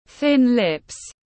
Môi mỏng tiếng anh gọi là thin lips, phiên âm tiếng anh đọc là /θɪn lɪp/ .